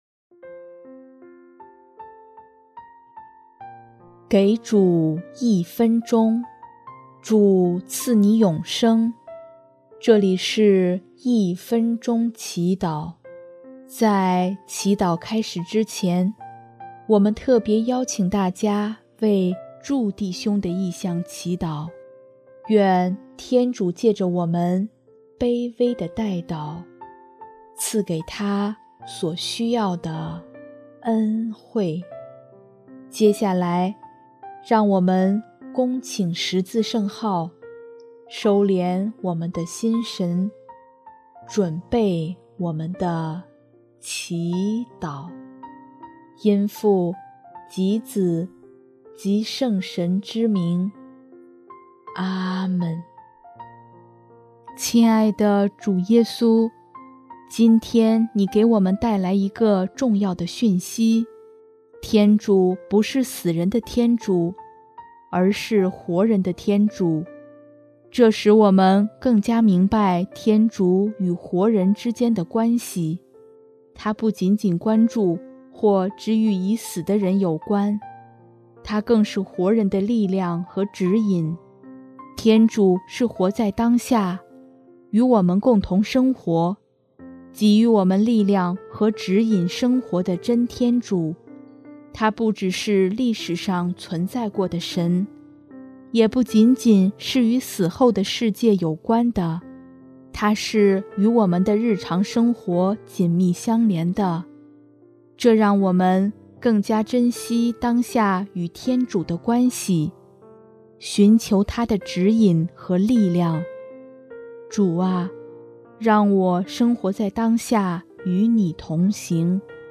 音乐： 主日赞歌